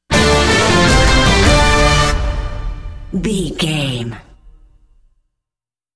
Ionian/Major
horns
drums
electric guitar
synthesiser
orchestral
orchestral hybrid
dubstep
aggressive
energetic
intense
strings
bass
synth effects
wobbles
heroic
driving drum beat
epic